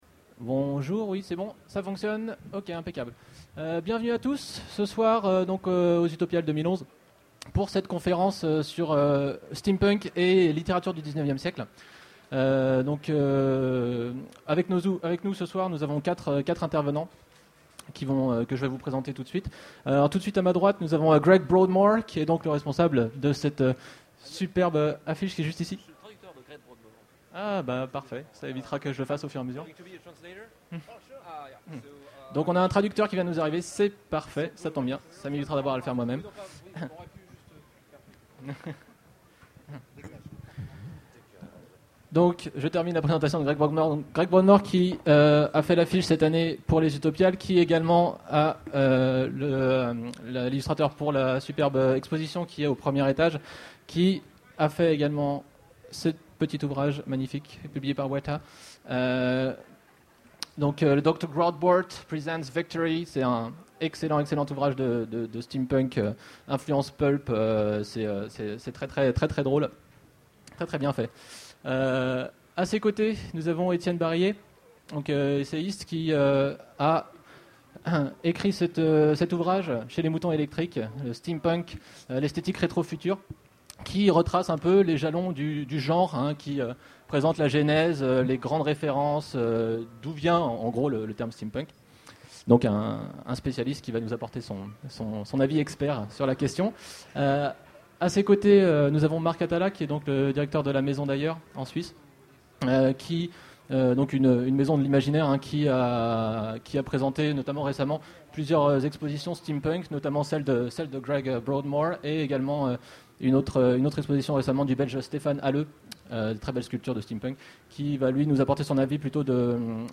Utopiales 2011 : Conférence Le steampunk rend-il hommage aux oeuvres littéraires du XIXème siècle ?